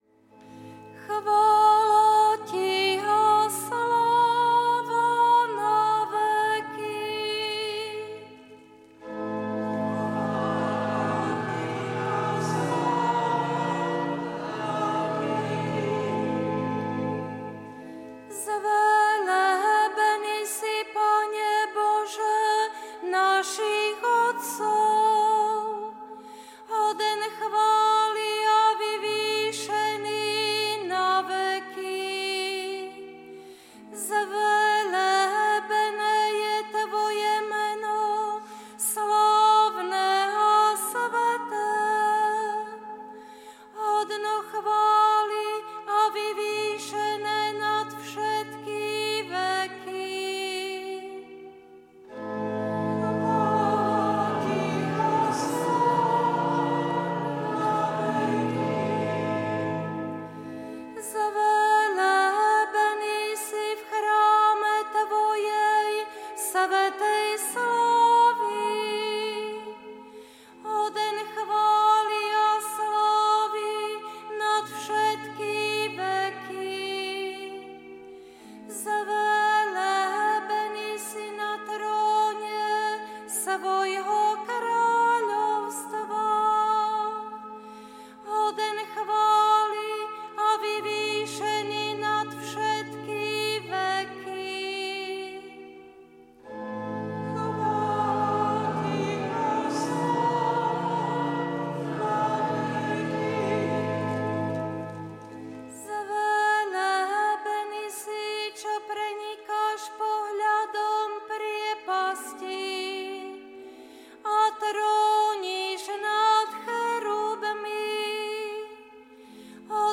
LITURGICKÉ ČÍTANIA | 9. apríla 2025